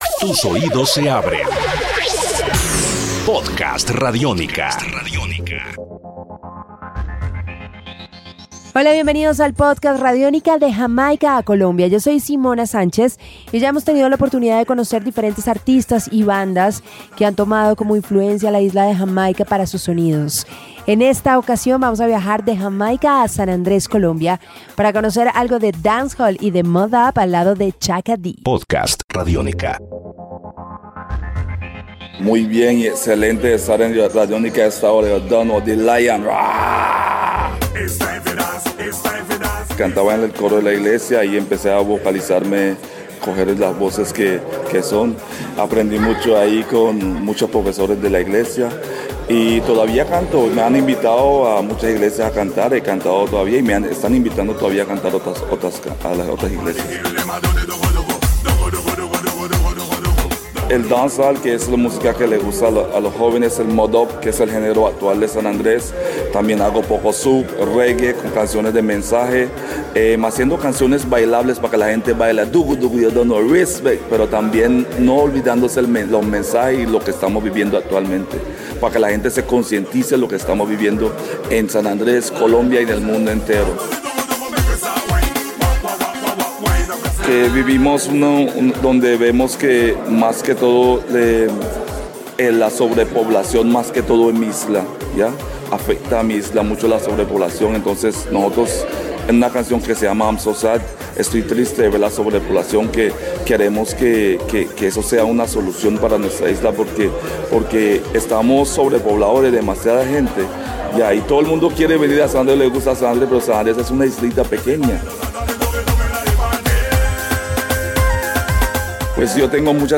El Mode Up es un género característico de la Isla de San Andrés.